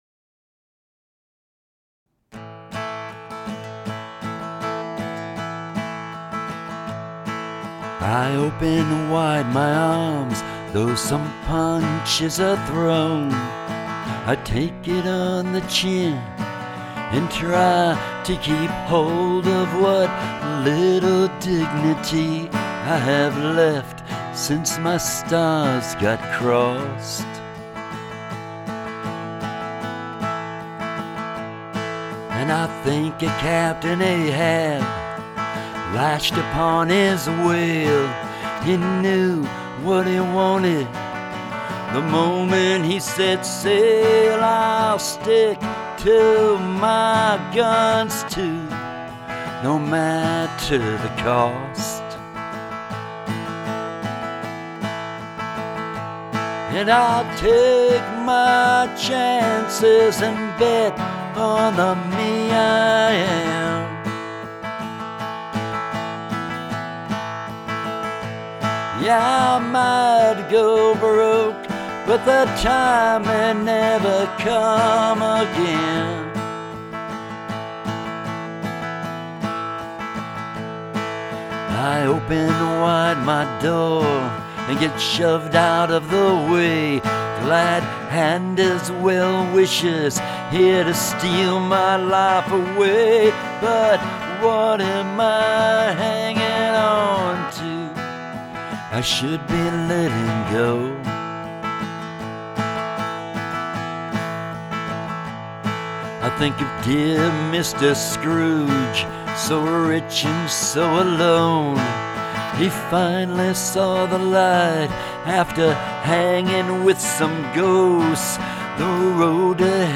compose an all acoustic album.